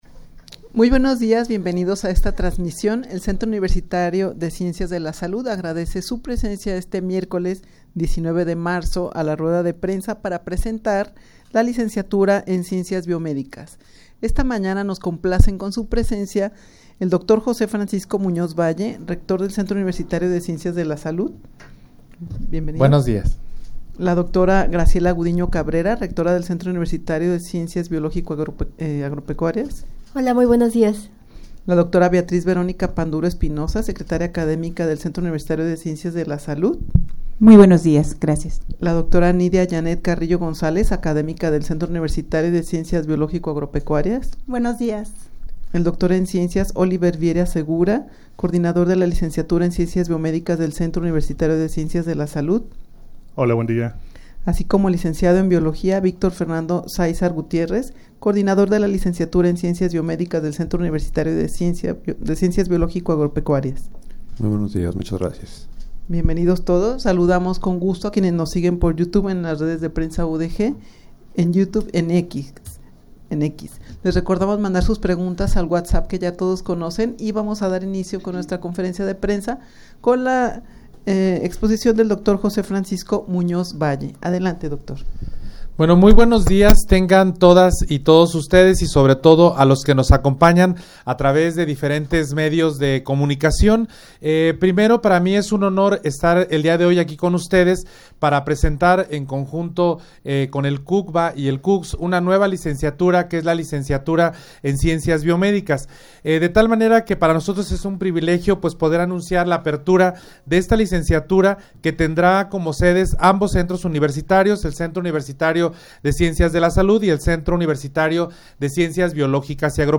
Audio de la Rueda de Prensa
rueda-de-prensa-presentacion-de-la-licenciatura-en-ciencias-biomedicas.mp3